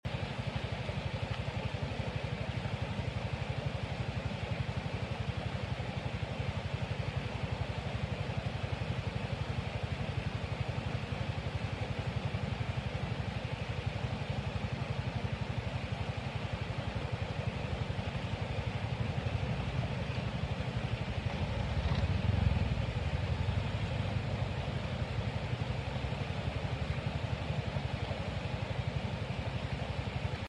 Suara Alam Ini Hangatkan Jiwa Sound Effects Free Download